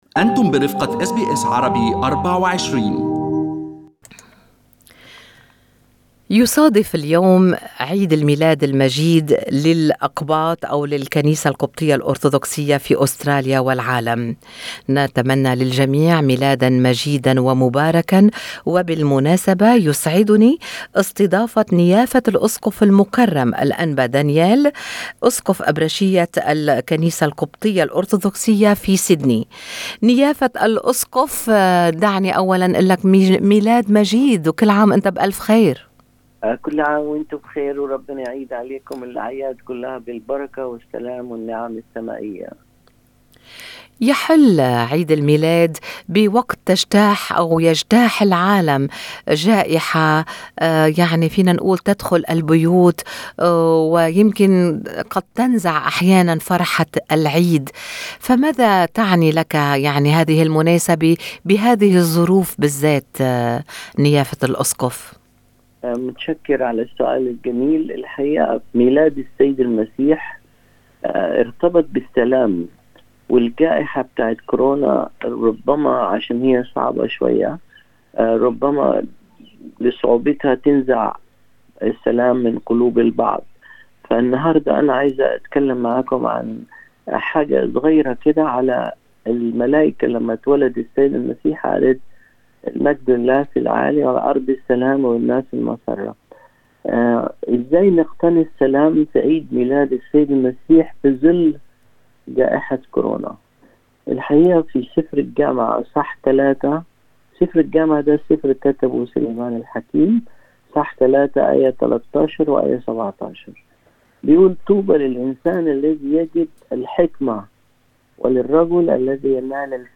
بهذه المناسبة، استضافت أس بي أس عربي24 نيافة الأسقف المكرم الأنبا دانييل أسقف أبرشية الكنيسة القبطية في سيدني.